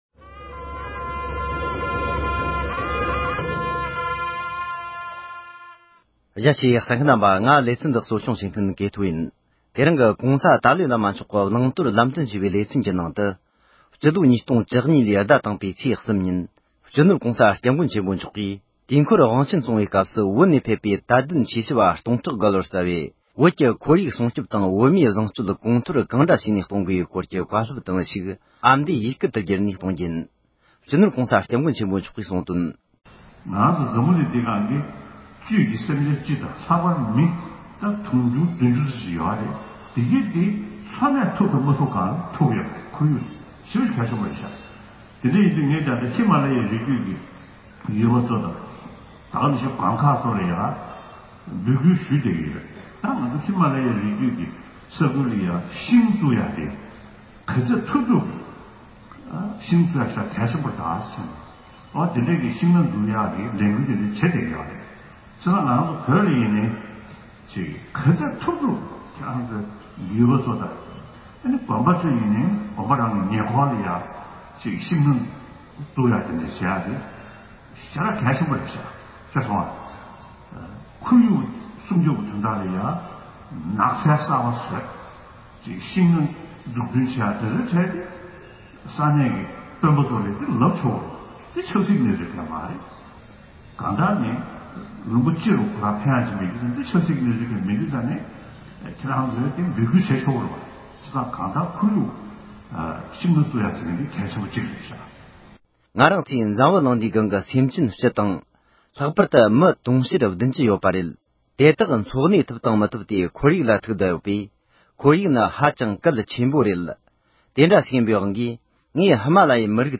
ཁོར་ཡུག་སྲུང་སྐྱོབ་དང་སྤྱོད་ལམ་ཡར་རྒྱས་སྐོར་བཀའ་སློབ།
༸གོང་ས་མཆོག་གིས་བོད་ཀྱི་ཁོར་ཡུག་སྲུང་སྐྱོབ་དང་སྤྱོད་ལམ་ཡར་རྒྱས་སྐོར་བསྩལ་བའི་བཀའ་སློབ།